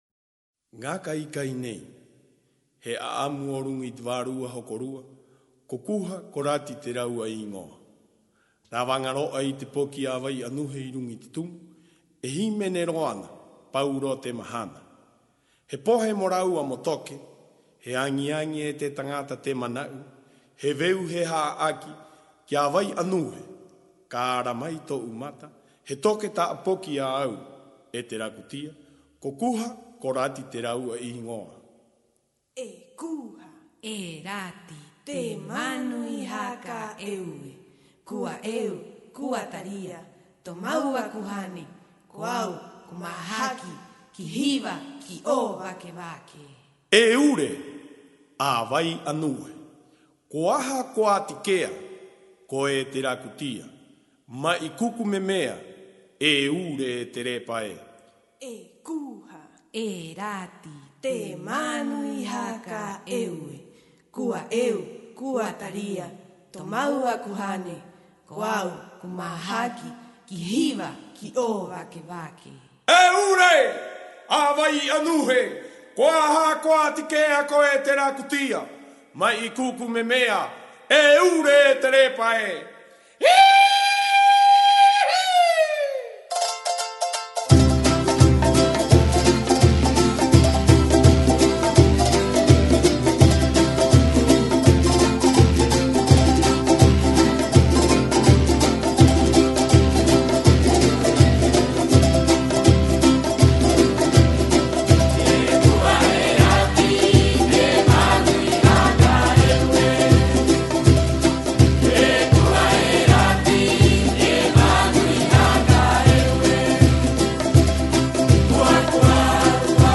И, как я понял, по этому поводу не помешает послушать еще одну песню из репертуара фольклорных коллективов о. Пасхи.